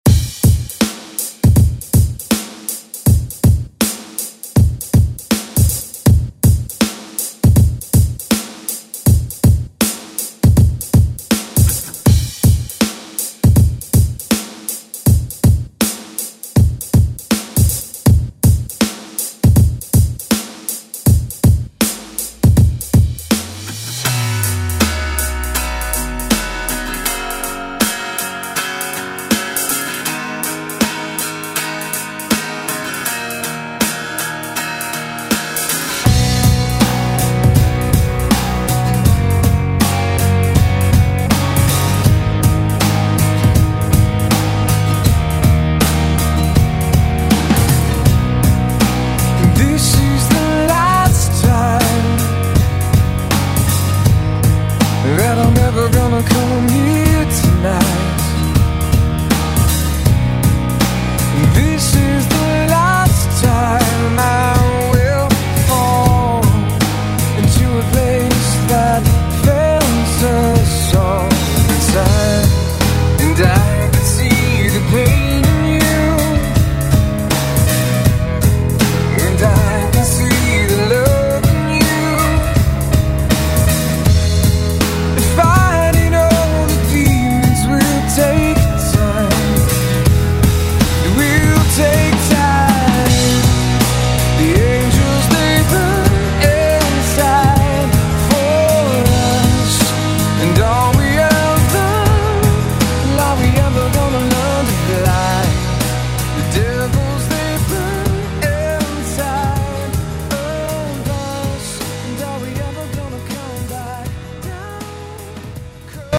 Electronic Alternative Pop Rock Music
Extended ReDrum Clean 80 bpm